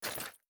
Grenade Sound FX
Throw7.wav